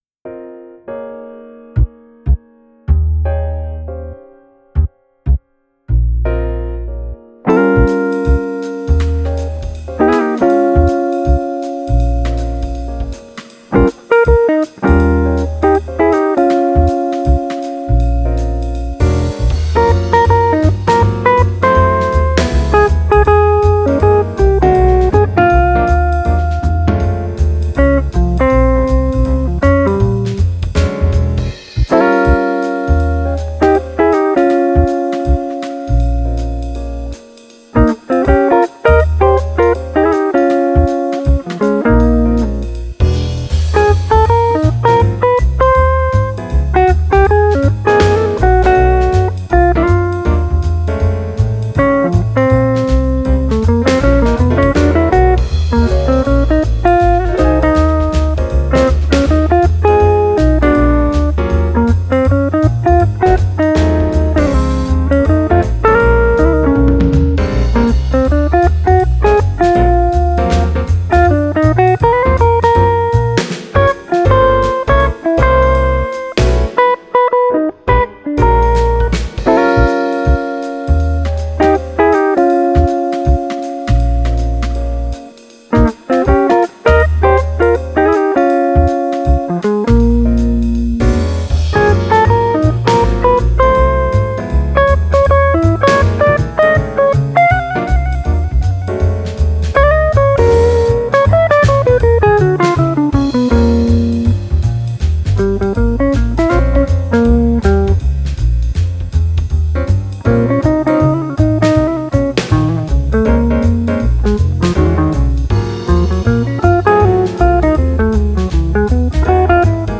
Very nice long jazz lines/phrases/.